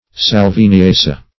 salviniaceae - definition of salviniaceae - synonyms, pronunciation, spelling from Free Dictionary
salviniaceae.mp3